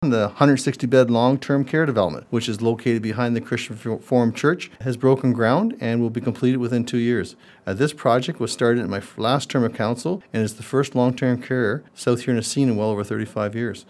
As we look to the new year, South Huron Mayor George Finch stopped by the myFM studio and highlighted the community’s accomplishments in 2024, reflecting on a year filled with resilience, collaboration, and growth.